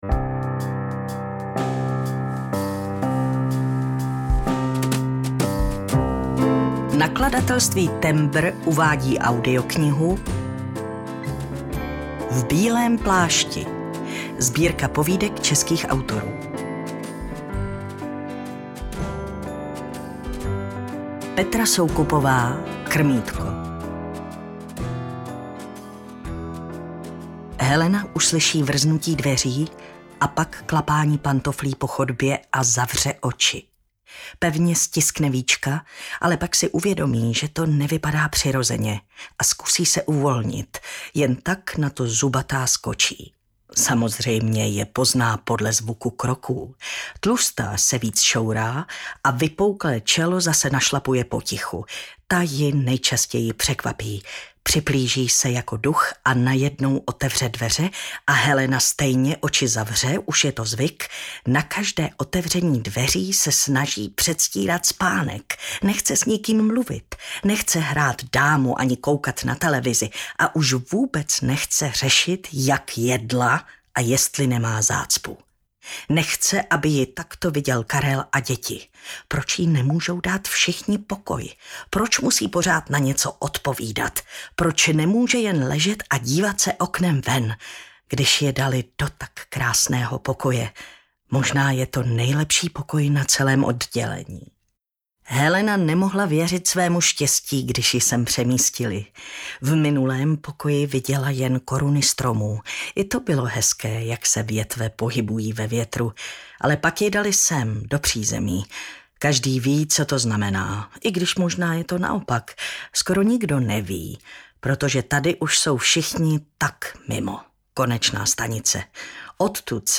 V bílém plášti - Petra Soukupová, Petra Dvořáková, Irena Dousková, Marek Epstein, Markéta Hejkalová, Alena Mornštajnová, Irena Hejdová - Audiokniha
• Čte: Jana Stryková, Martina Hudečková,…